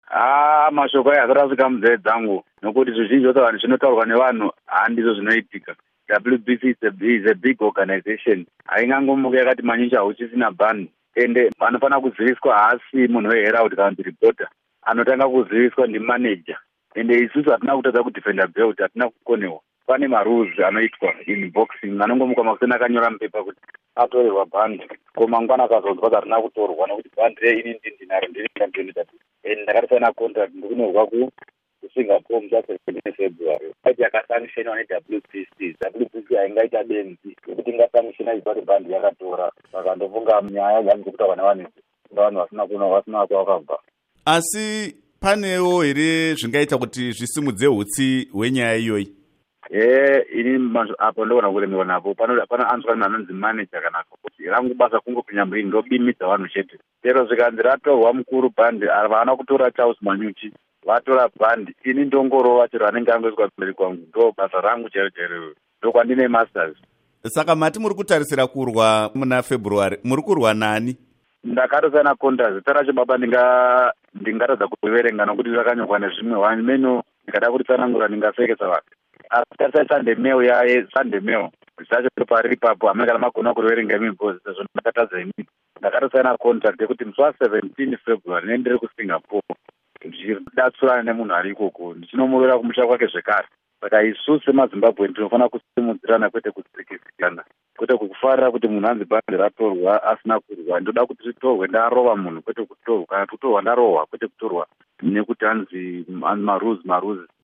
Hurukuro naCharles Manyuchi